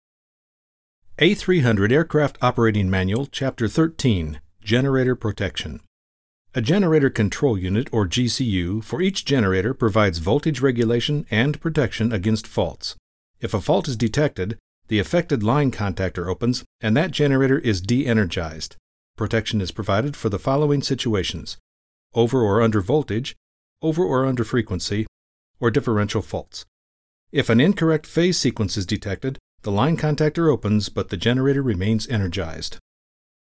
English middle aged guy next door warm believable smooth voice.
middle west
Sprechprobe: Industrie (Muttersprache):